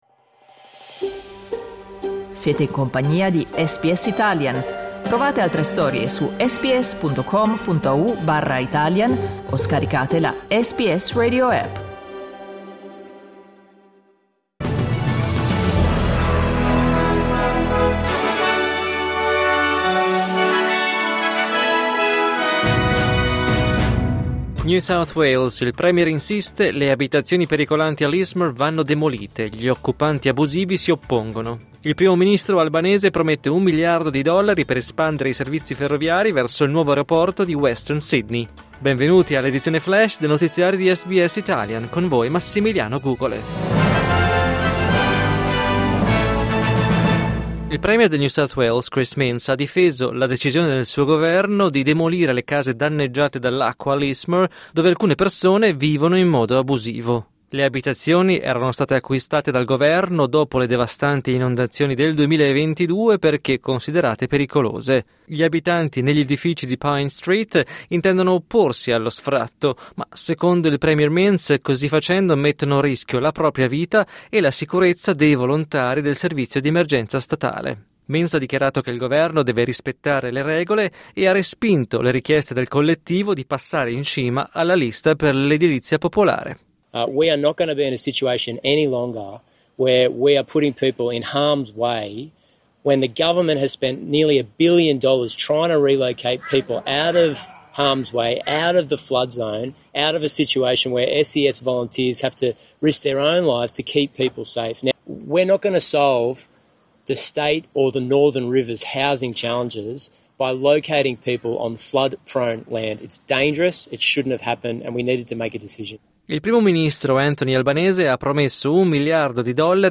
News flash giovedì 13 marzo 2025